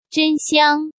中国語音声の発音付きなのでチェックしながら勉強できるよ！